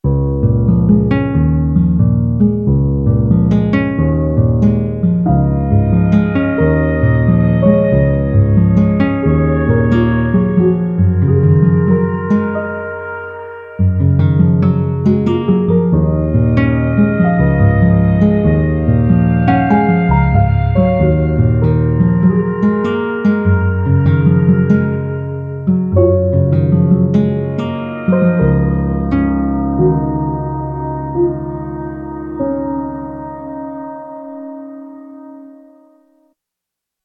I get pretty serious option paralysis so there was something appealing about having and using only whats onboard the PX when I wanted these more pedestrian “real instrument” type sounds.
But for now, PX sans mayhem.